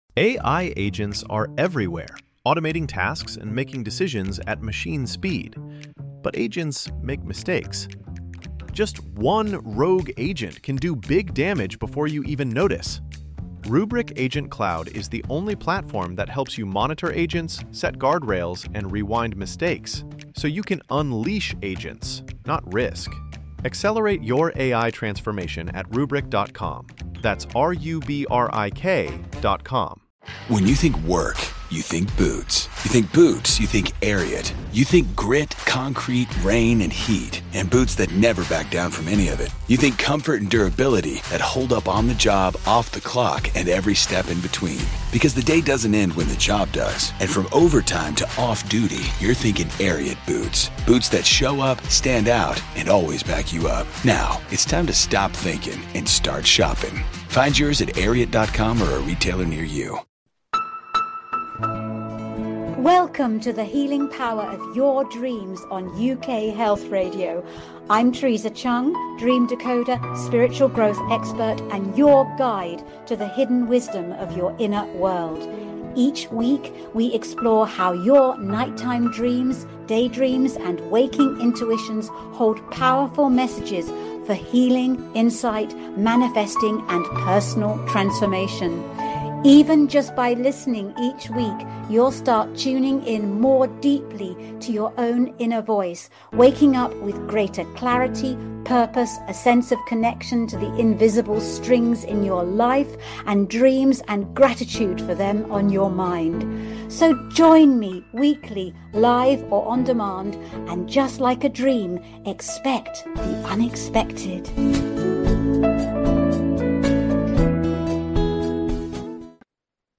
The Healing Power of Dreams explores the transformative power of nocturnal dreams and day dreams for your mental, emotional, physical, and spiritual wellbeing. Each live episode dives deep with leading scientists, psychologists, authors, and consciousness researchers, plus the occasional celebrity guest sharing their own dream stories.